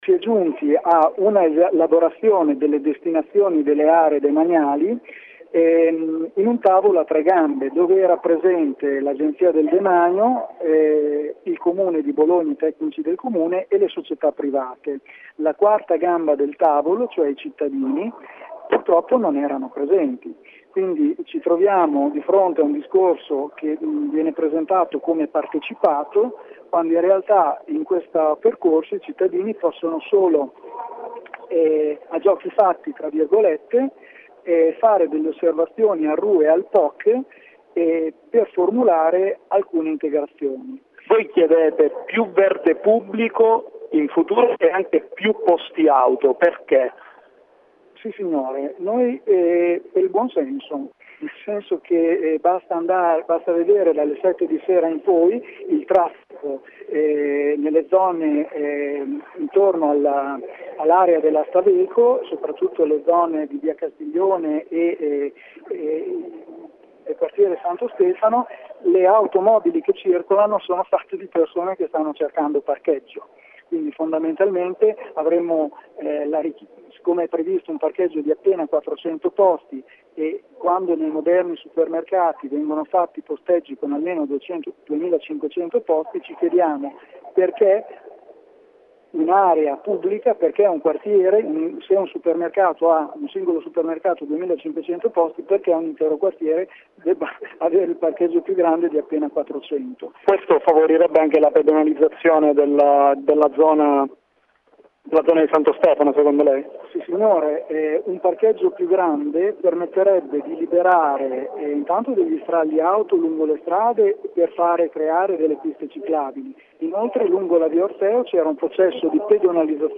In occasione della presentazione dell’intesa, oggi pomeriggio a Palazzo D’Accursio, diversi residenti hanno manifestato le loro perplessità sulla destinazione delle aree che insistono sui rispettivi quartieri.